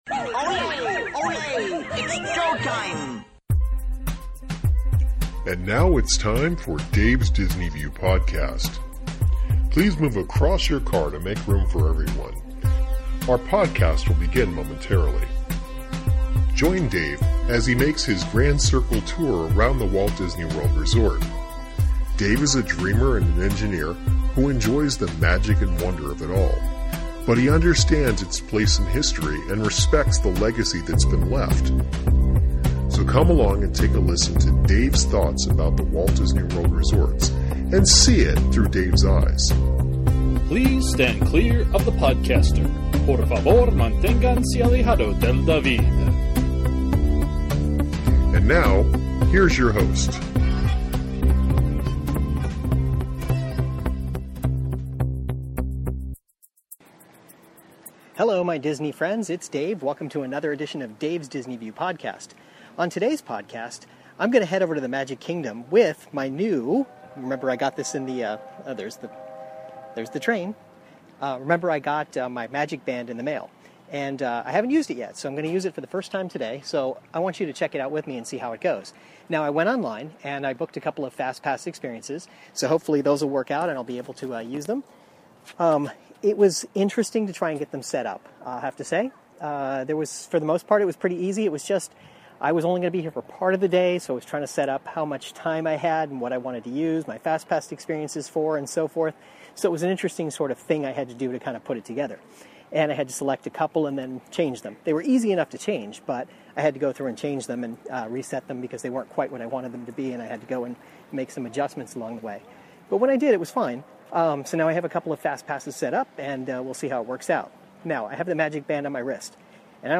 Today, I am in the Magic Kingdom trying out my MagicBand, FastPass+, and checking out some of the changes that have taken (or are taking!) place. Come and take a listen as I spend my day wandering around and telling stories...